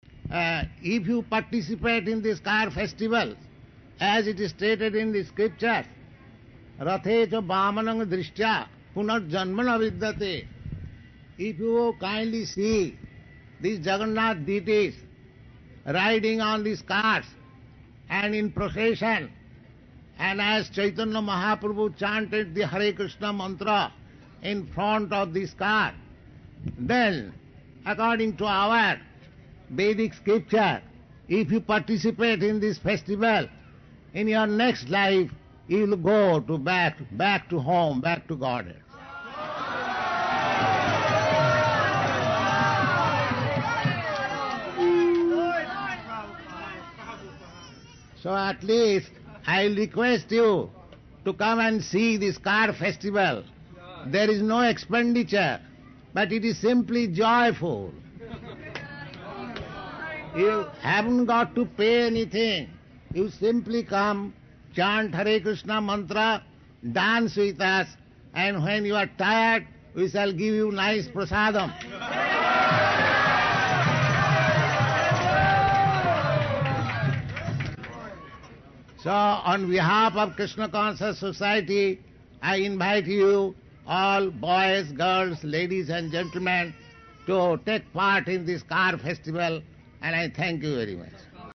Srila Prabhupada Lectures on Ratha Yatra
(700705 – Lecture Festival Ratha-yatra and Press Conference – San Francisco)